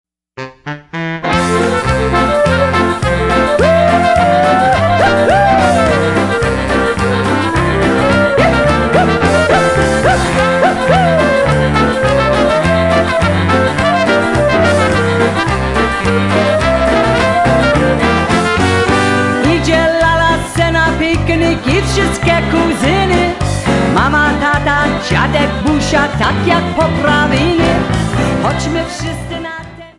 Polkas, Waltzes and Obereks